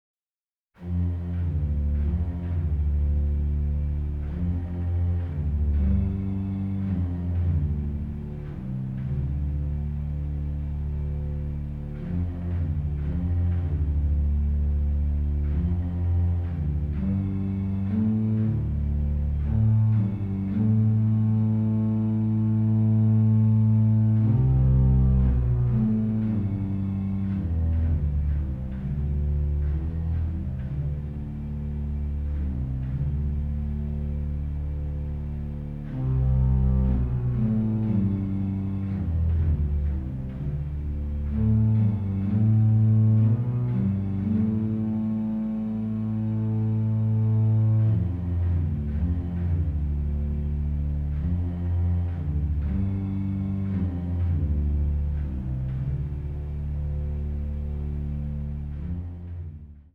colorful orchestral tapestry is a true exotic delight.